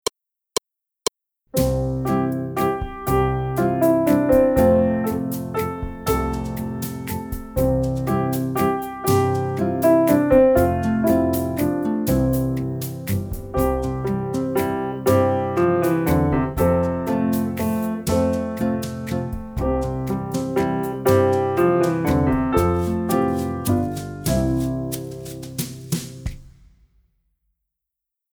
합주